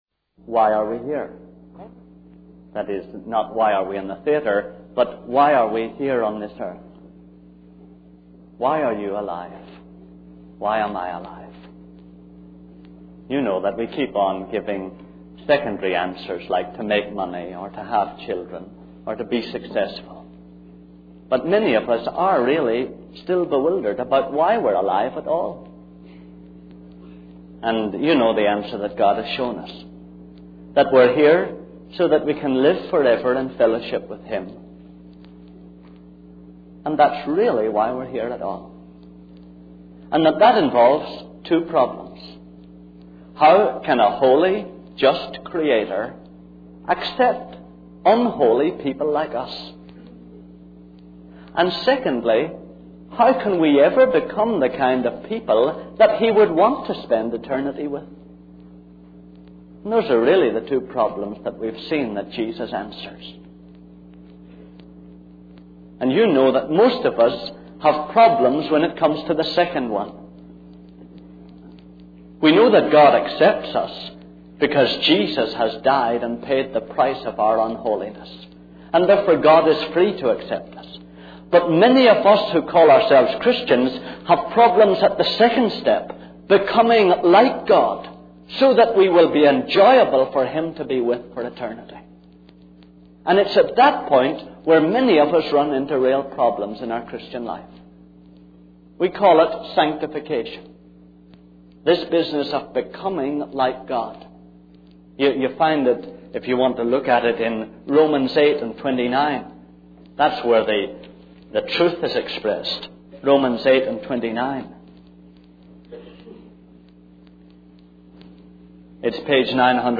In this sermon, the speaker discusses the concept of love and acceptance in the world. He points out that people tend to love and accept others based on their attractiveness or compatibility. However, he emphasizes that human love is nothing compared to God's love.